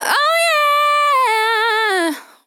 Categories: Vocals Tags: dry, english, female, fill, LOFI VIBES, OH, sample, YEAH